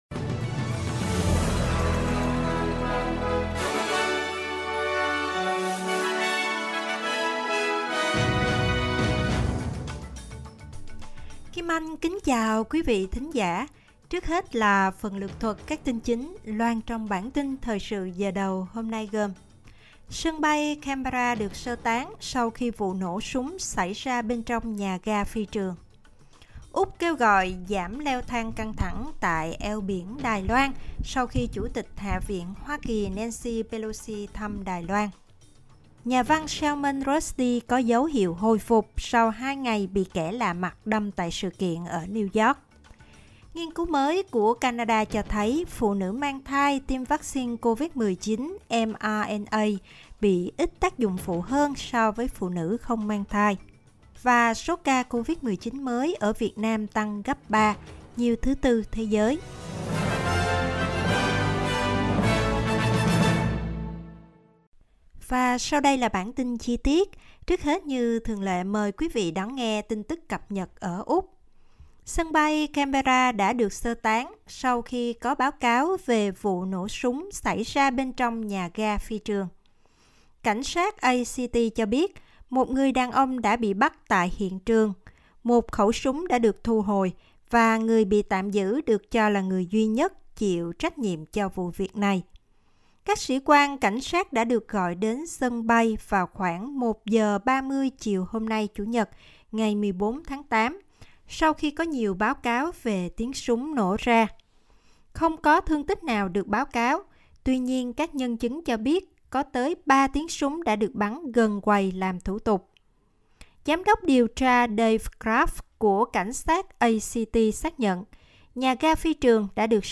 Một số tin chính trong ngày của SBS Radio.